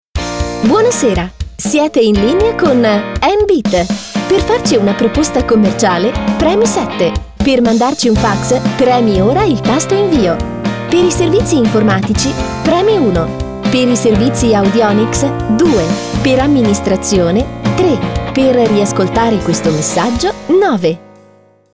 Basi musicali